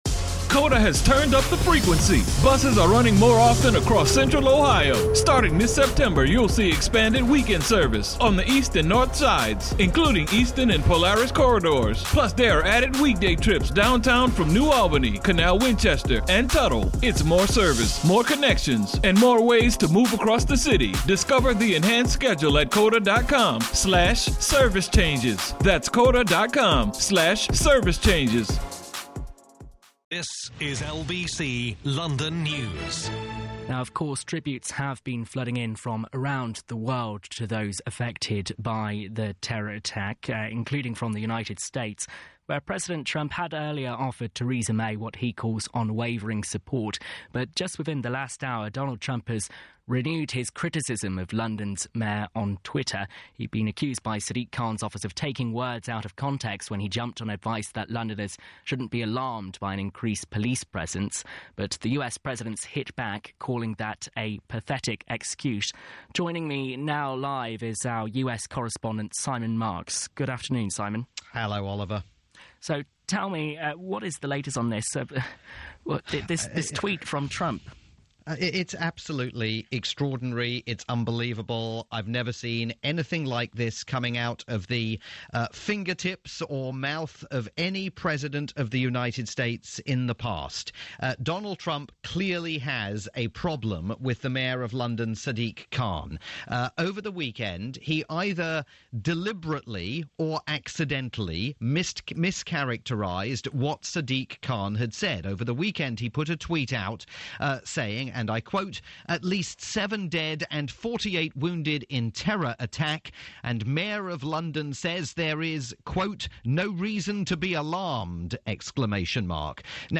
latest report aired on London's rolling news station LBC London News.